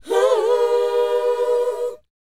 WHOA F#D.wav